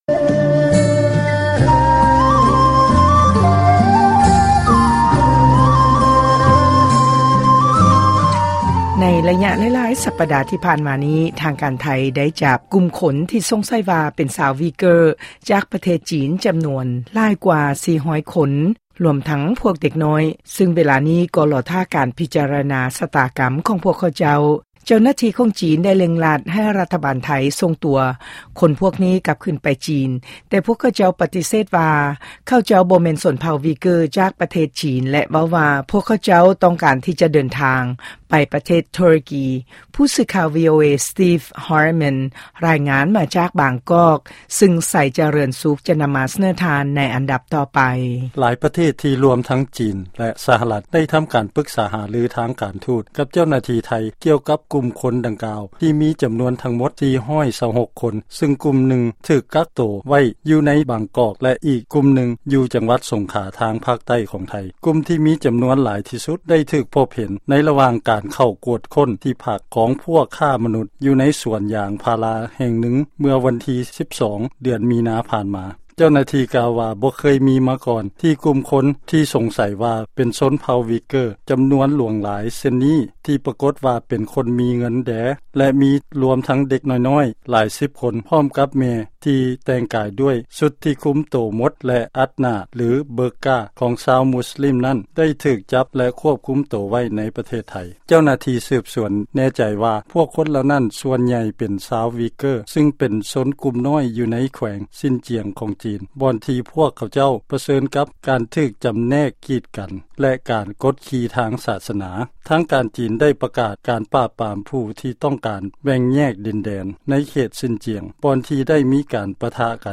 ຟັງລາຍງານເລື້ອງ ຊາວ ວີເກີ້ ທີ່ຖືກທາງການໄທ ກັກຂັງ.